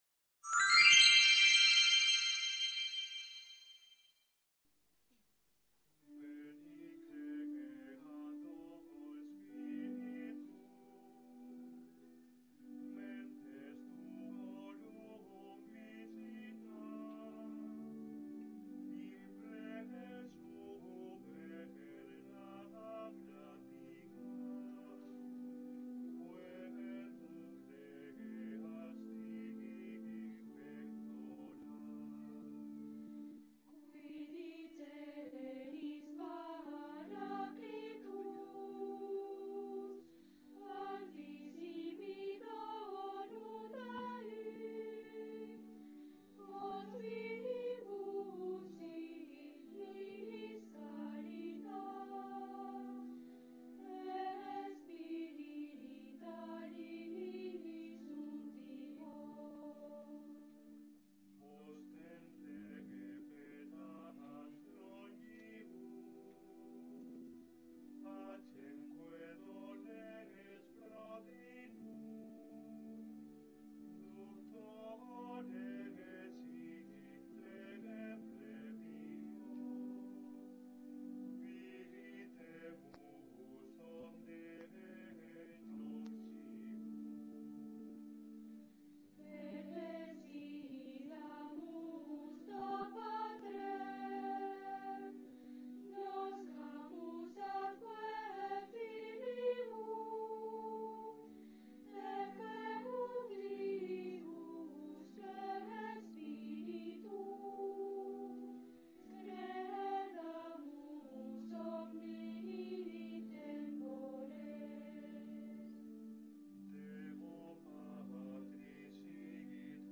Solemne Acto Académico de Apertura del Curso en la UNED de Calatayud